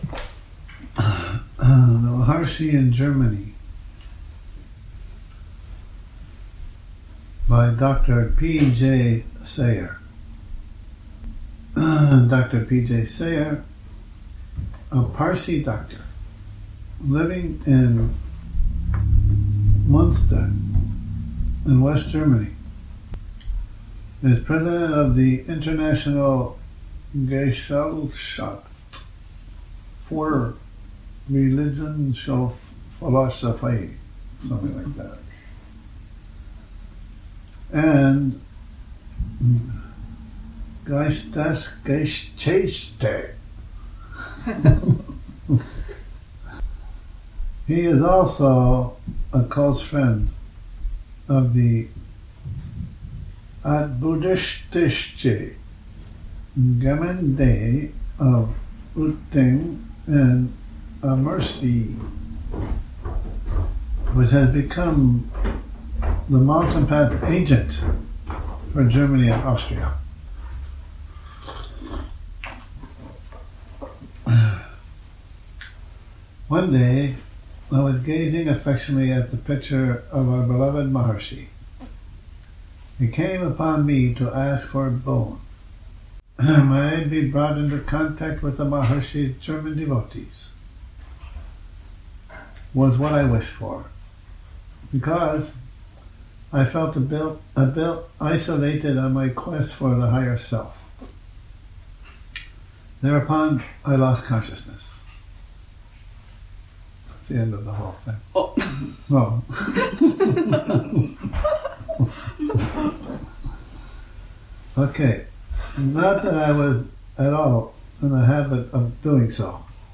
Morning Reading, 20 Nov 2019